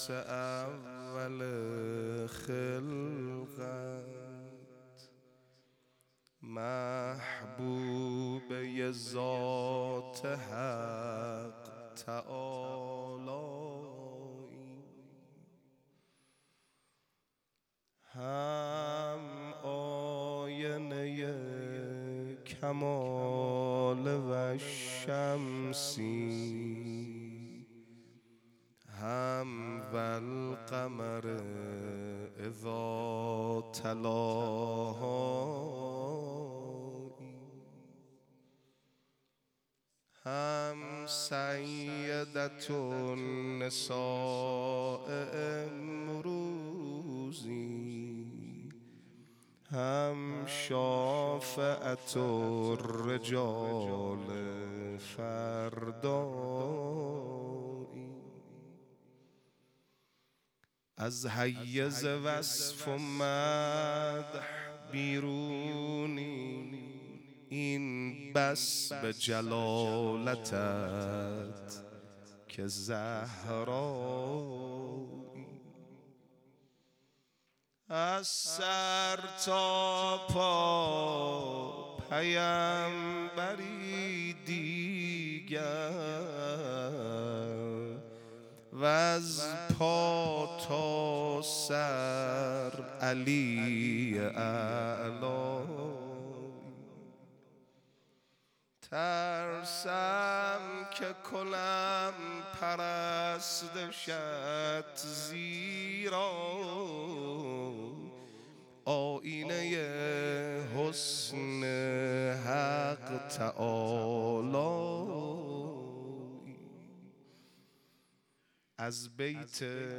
روضه حضرت زهرا(س)
ایام فاطمیه